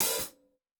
TC Live HiHat 10.wav